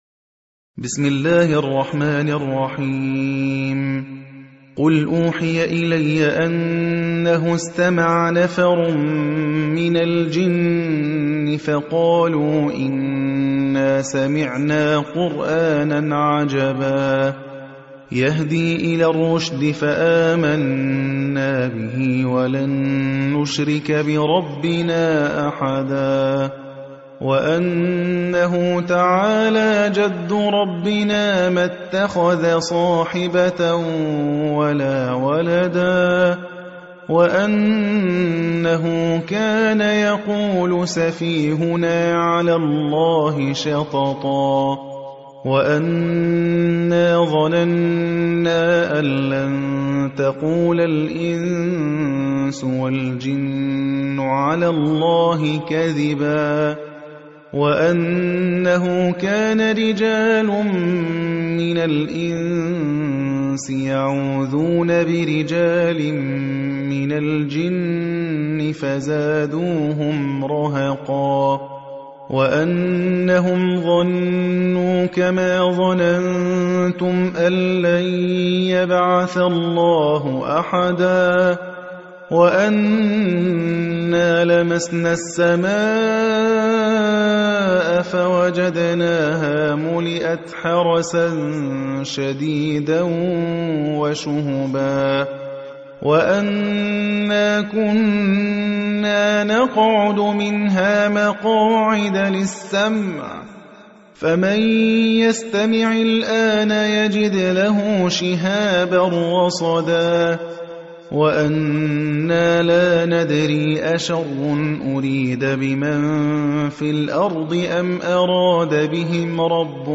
روایت حفص از عاصم